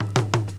Roll (3).wav